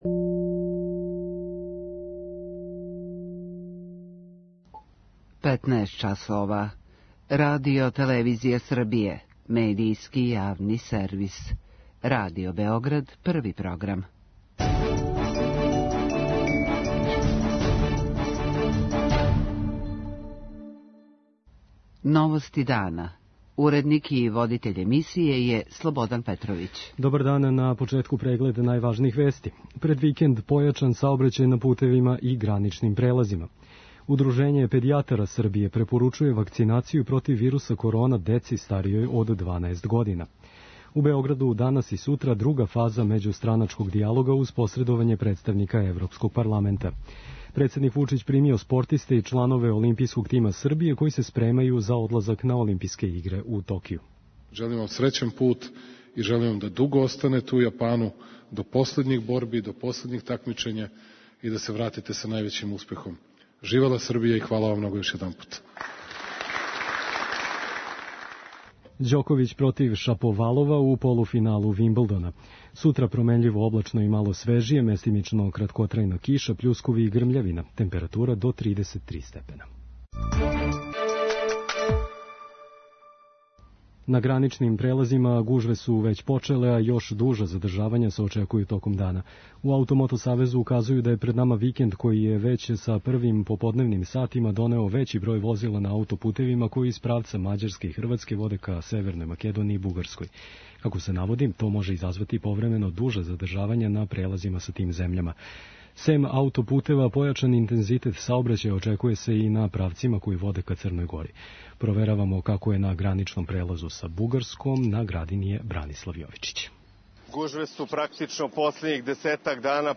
Туристички радници кажу да ће многи током предстојећег викенда путовати на летовање. преузми : 6.29 MB Новости дана Autor: Радио Београд 1 “Новости дана”, централна информативна емисија Првог програма Радио Београда емитује се од јесени 1958. године.